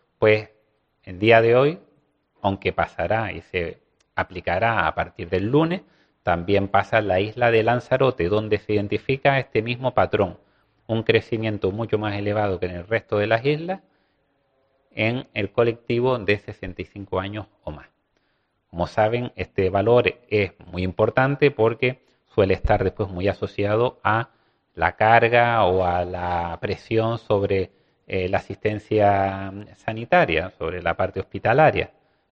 Antonio Olivera, viceconsejero de Presidencia del Gobierno de Canarias.